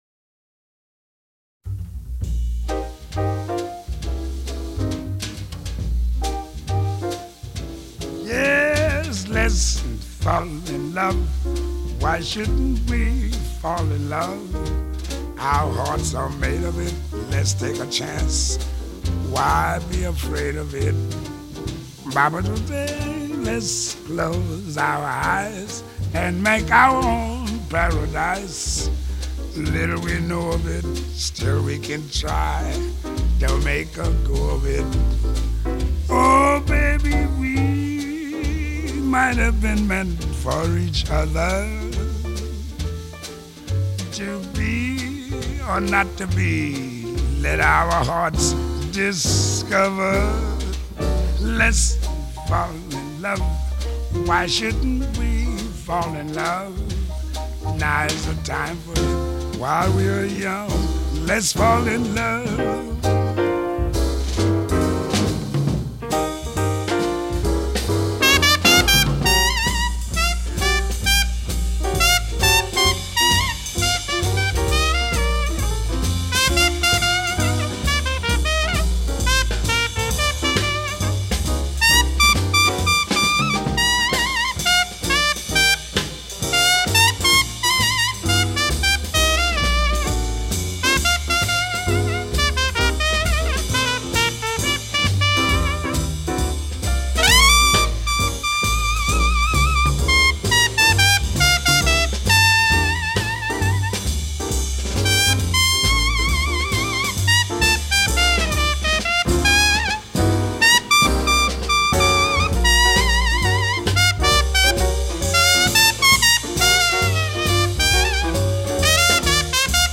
[light]獨特悠閒的音樂空間，36首最能掌握時代脈動的爵士樂精選 !